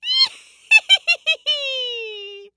horror
Witch Female Laugh 3